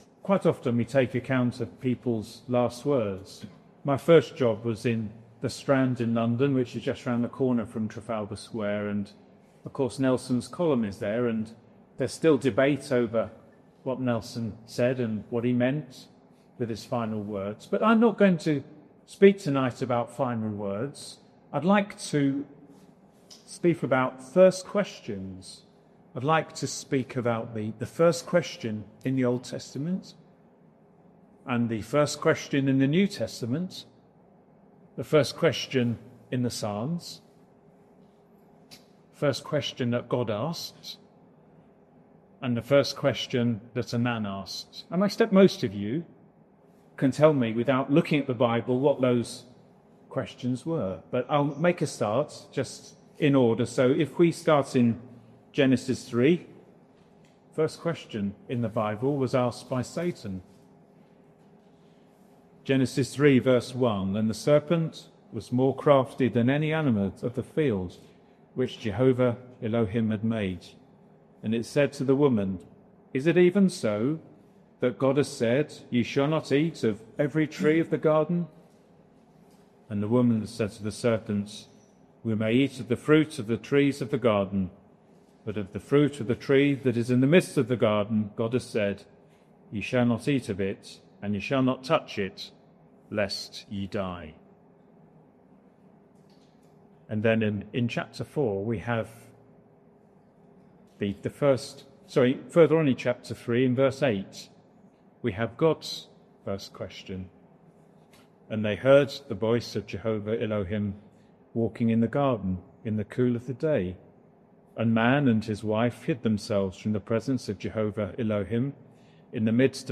This gospel preaching focuses on the first questions found in the Bible—the first question in the Old Testament, the searching questions raised in the Psalms, and the first question of the New Testament. Through these early questions, we are led to Christ, the Saviour of the world, who comes not only to question the heart of man, but to answer our deepest need through His saving grace.